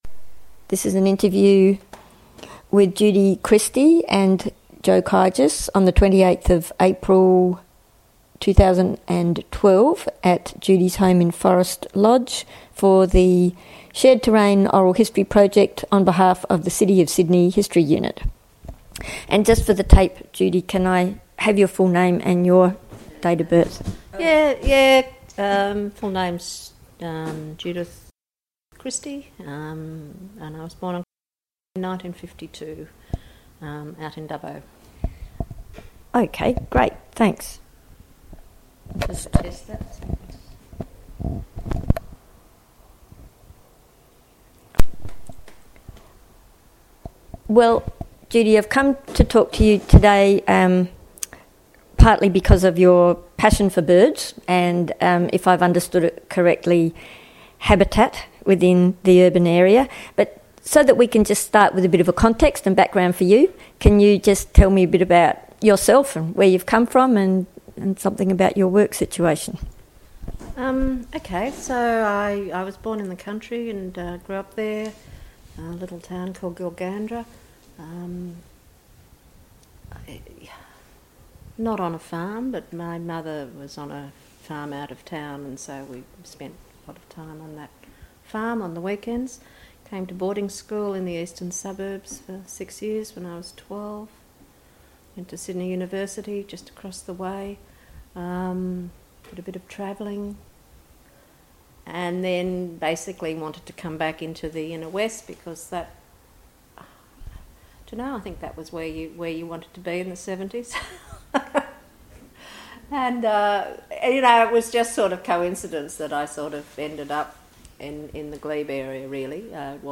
This interview is part of the City of Sydney's oral history theme: Shared Terrain